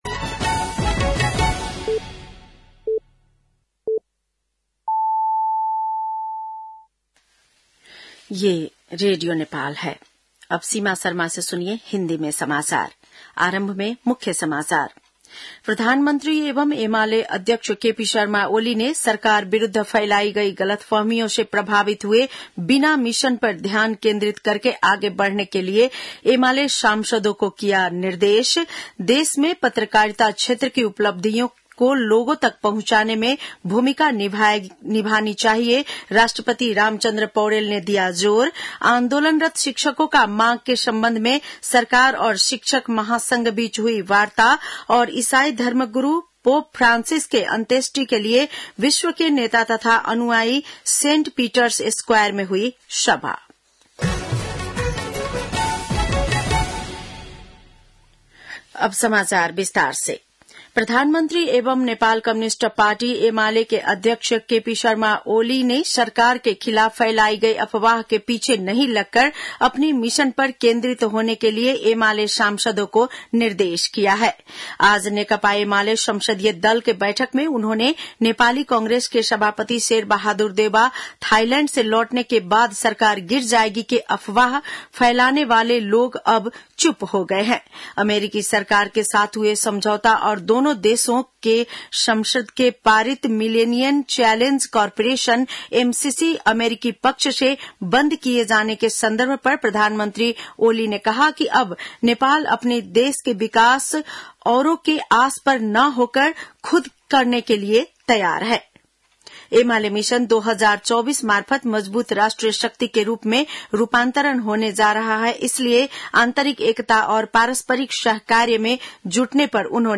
बेलुकी १० बजेको हिन्दी समाचार : १३ वैशाख , २०८२